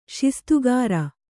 ♪ śistugāra